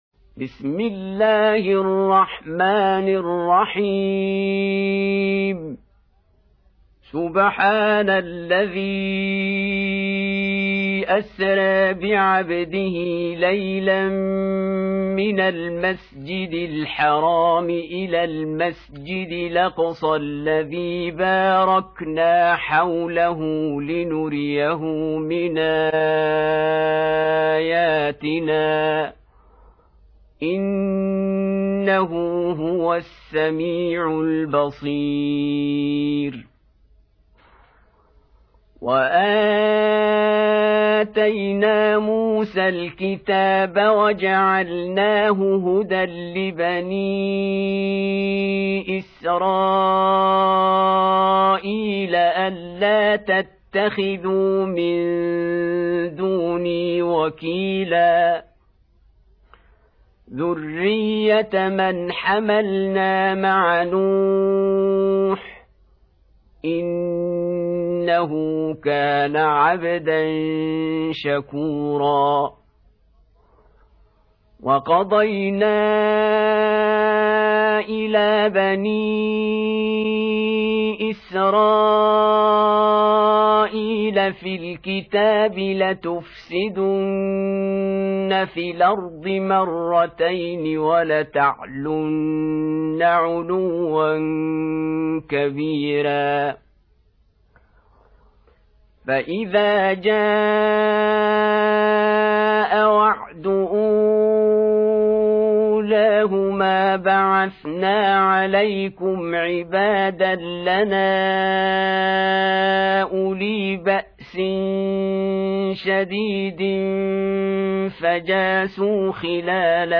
Audio Quran Tarteel Recitation
Surah Repeating تكرار السورة Download Surah حمّل السورة Reciting Murattalah Audio for 17. Surah Al-Isr�' سورة الإسراء N.B *Surah Includes Al-Basmalah Reciters Sequents تتابع التلاوات Reciters Repeats تكرار التلاوات